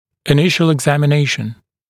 [ɪ’nɪʃl ɪgˌzæmɪ’neɪʃn] [eg-][и’нишл игˌзэми’нэйшн] [эг-]первичный осмотр